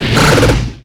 Cri de Feurisson dans Pokémon X et Y.